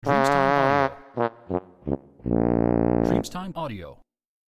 Carillon di venire a mancare della tromba del fumetto
• SFX